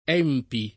vai all'elenco alfabetico delle voci ingrandisci il carattere 100% rimpicciolisci il carattere stampa invia tramite posta elettronica codividi su Facebook ENPI [ $ mpi ] n. pr. m. — sigla di Ente Nazionale per la Prevenzione degli Infortuni (1952-78)